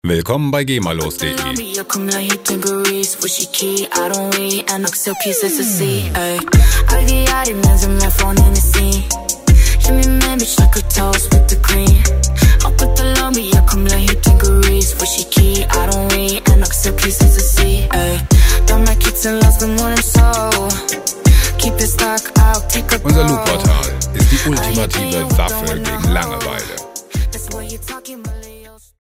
gema-freie Hip-Hop Loops
Musikstil: Trap
Tempo: 163 bpm